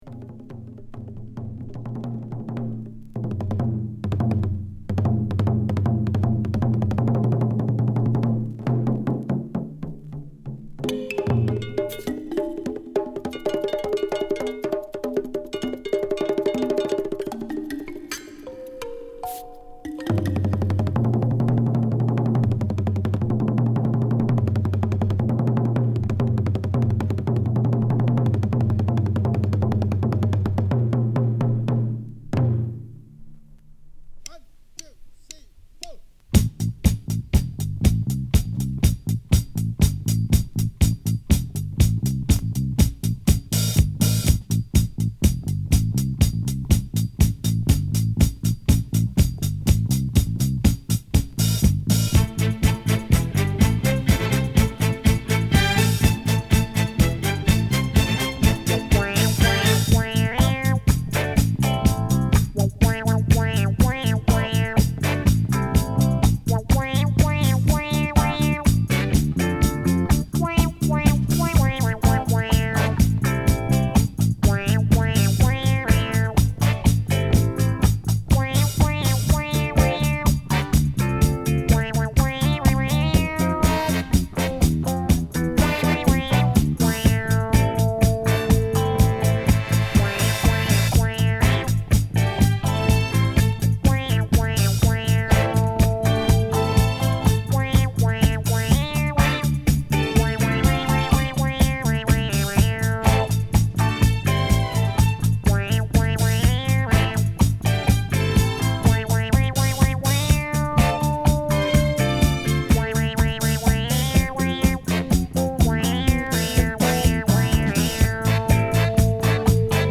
日本が生んだ超絶ファンキー・ドラマー
ドラム・ブレイクを散りばめたダイナミックな